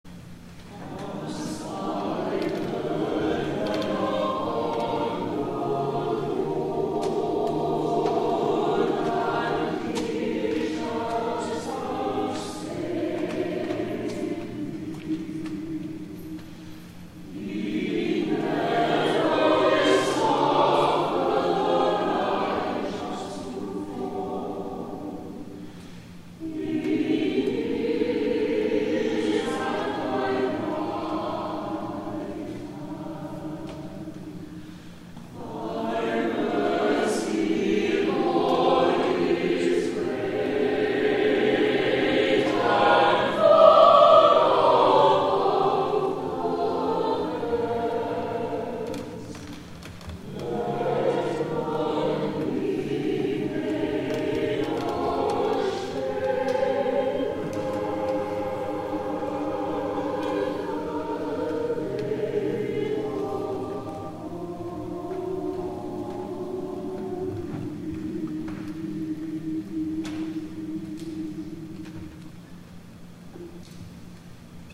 11 A.M. WORSHIP
*THE CHORAL RESPONSE